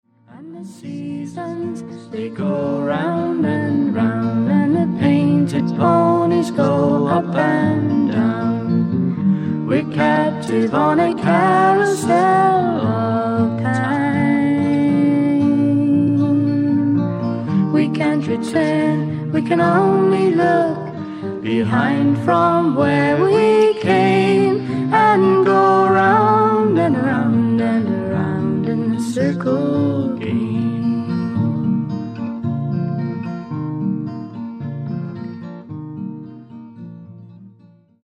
FEMALE FOLK / PSYCHEDEIC POP